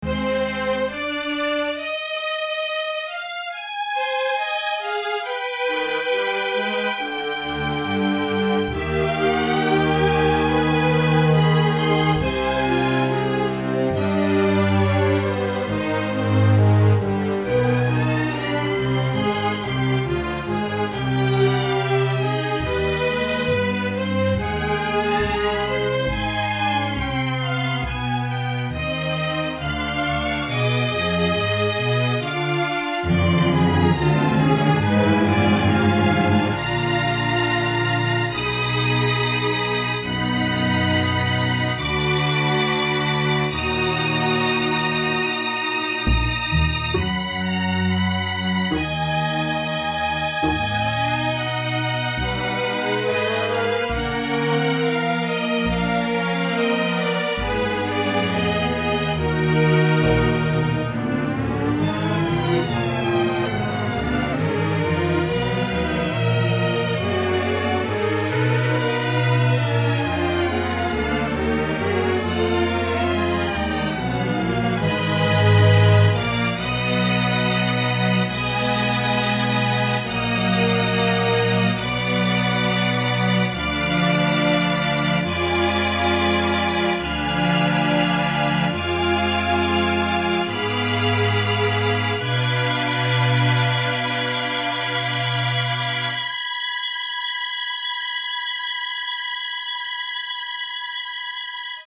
Chamber Symphony